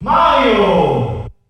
The announcer saying Mario's name in German releases of Super Smash Bros.
Mario_German_Announcer_SSB.wav